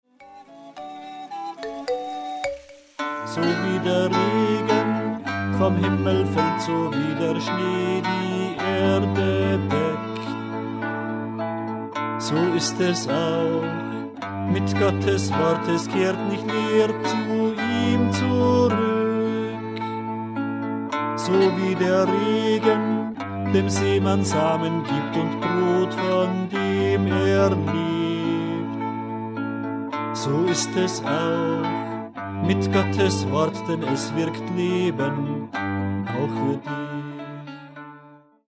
Eine gesungene Meditation über Jes 55,10-11